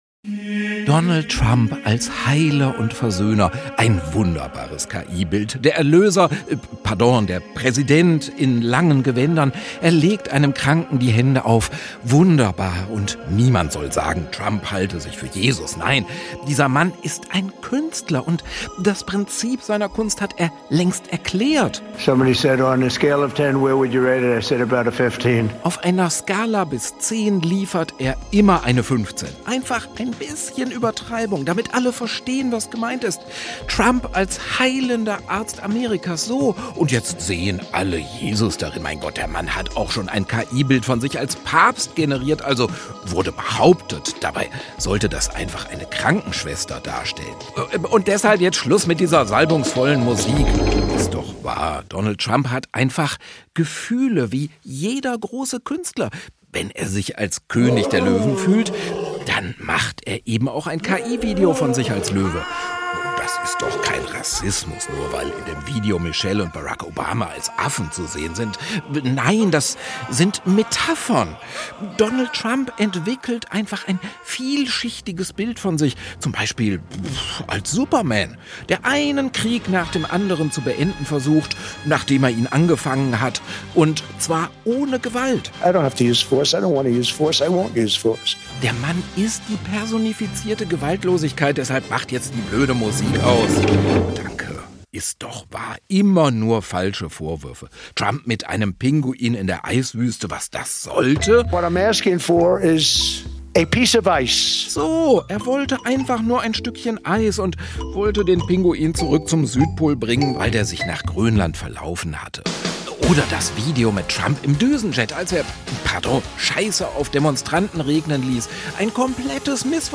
Glosse über einen Präsidenten mit Heiland-Fantasien